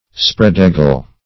spread-eagle \spread"-ea`gle\ (spr[e^]d"[=e]`g'l), a.